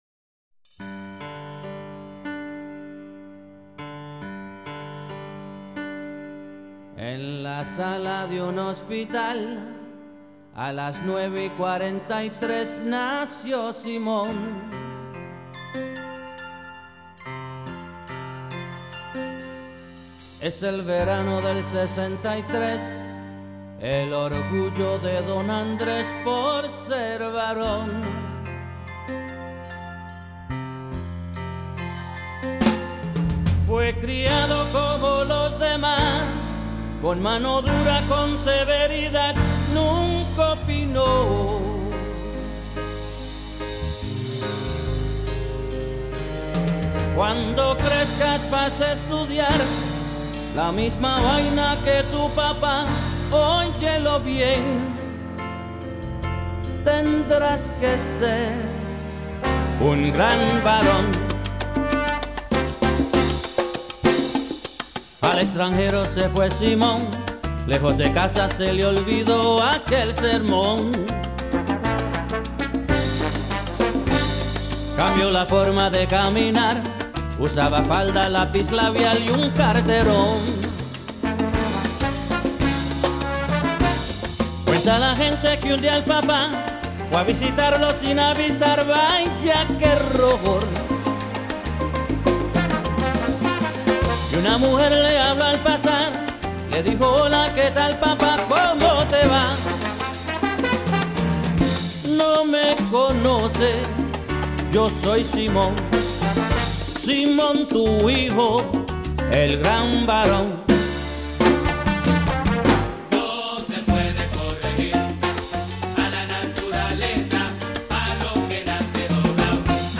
припев (хор):